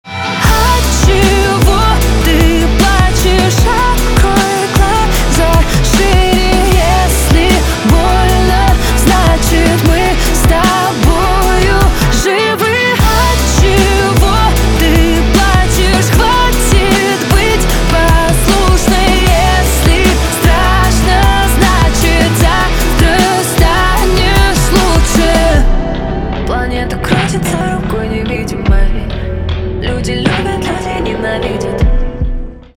поп
гитара , барабаны
чувственные